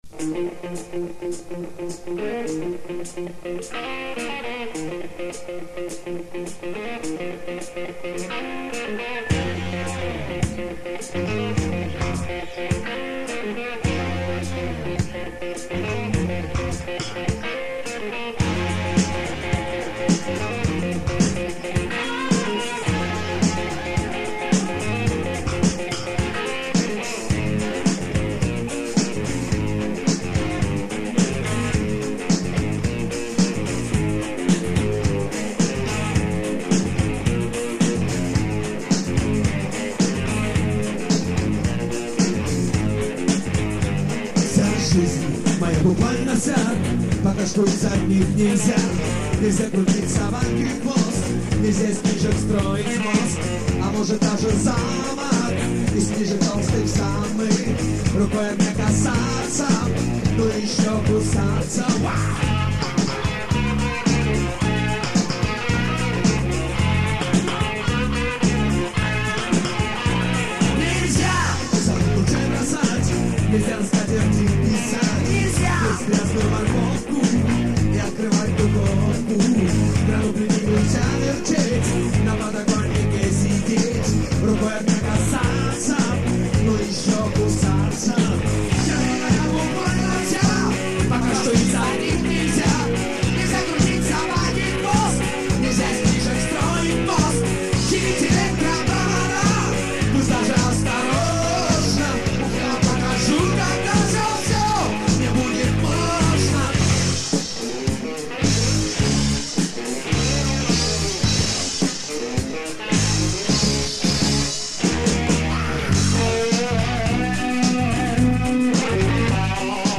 Надо всё привести в порядок,так как это кассета .